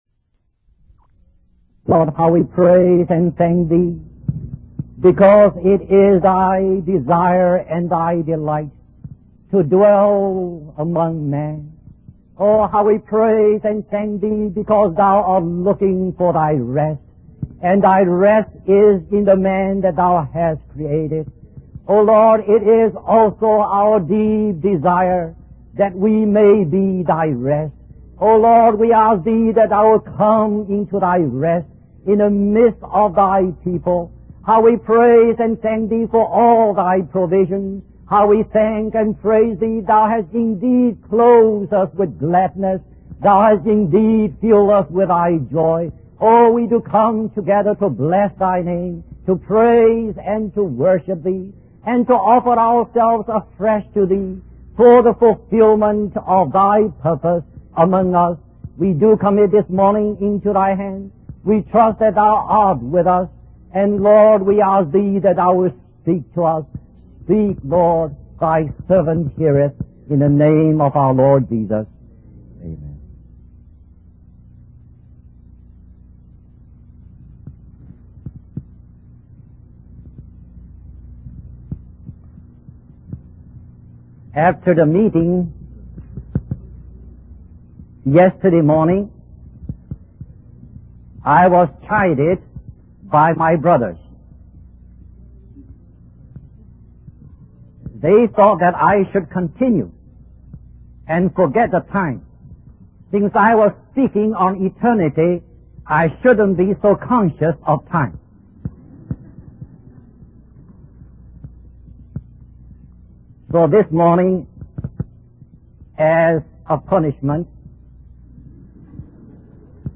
In this sermon, the speaker emphasizes the importance of understanding the Church from God's viewpoint. They highlight that God has a purpose for the Church and has laid out a plan to fulfill that purpose.